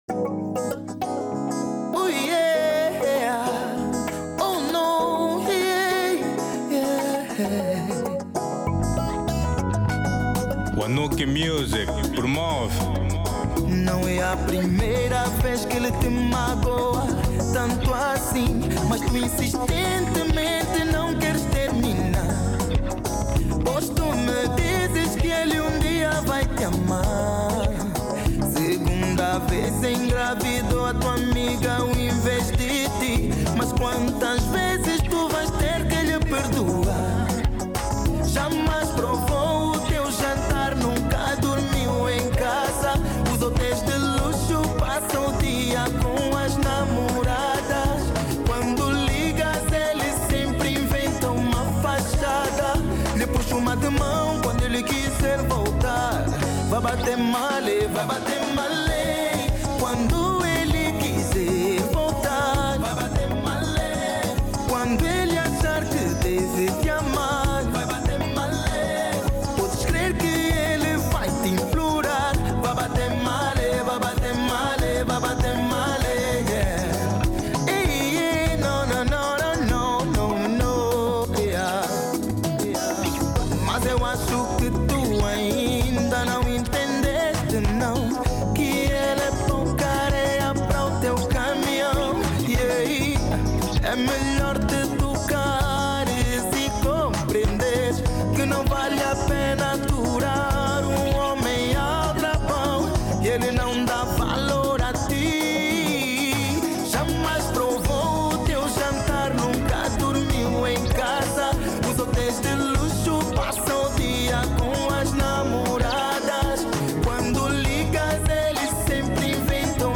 Genero: Semba